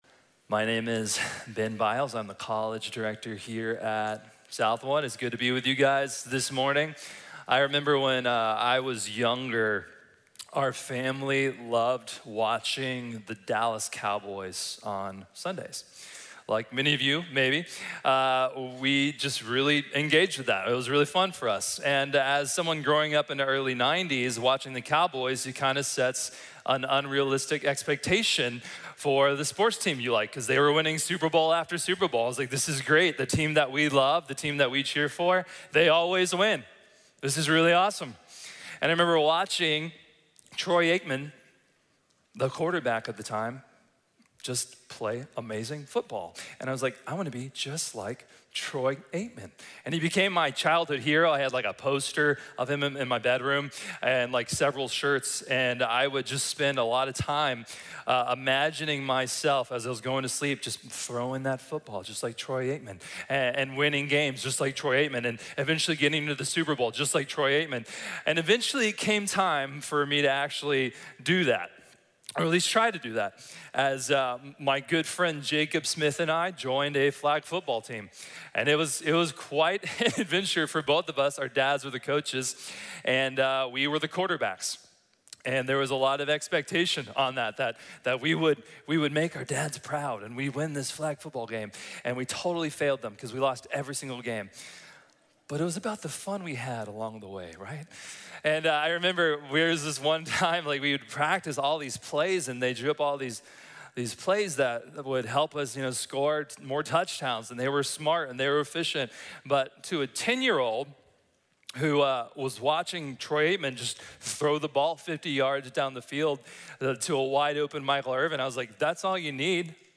Ecclesiology | Sermon | Grace Bible Church